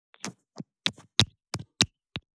450リップクリーム,口紅,ふたを開ける,
効果音